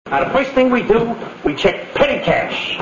Ross Martin played Godfrey, a bum down on his luck, in this second season episode, "Mork and the Bum Rap."